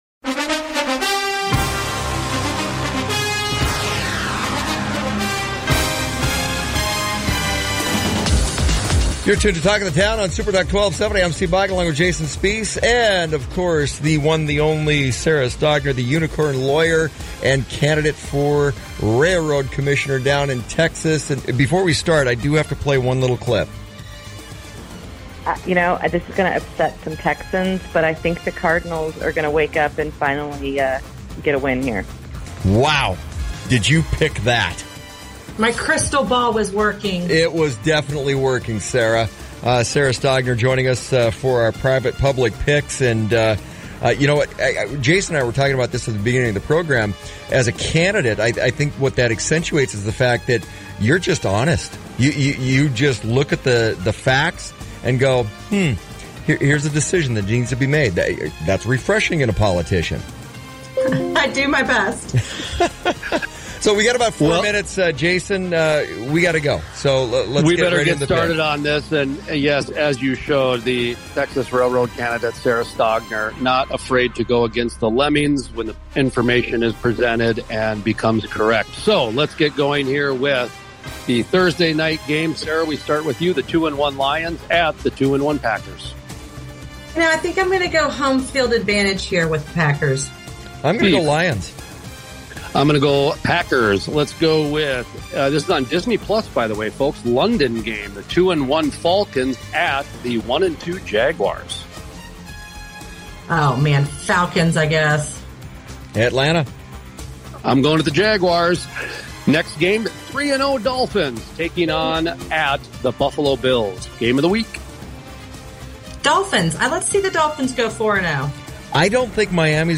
play NFL Pick ‘Em live on Townsquare Media SuperTalk 1270AM’s Talk of the Town